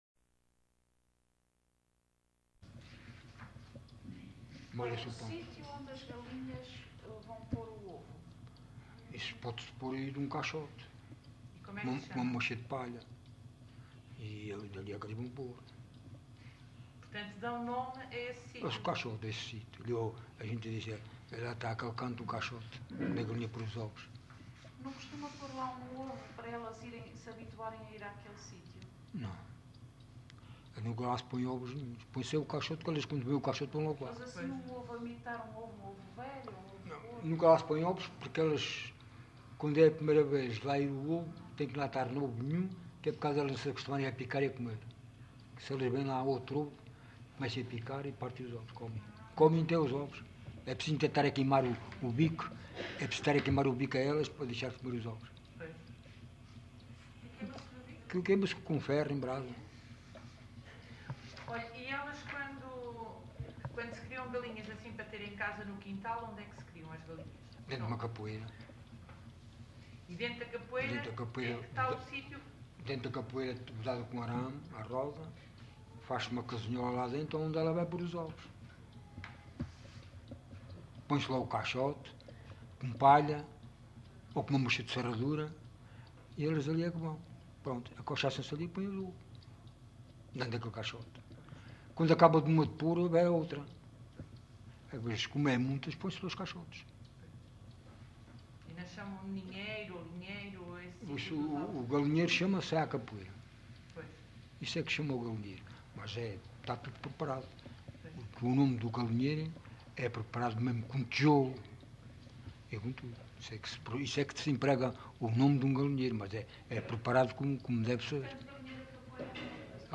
LocalidadeAlcochete (Alcochete, Setúbal)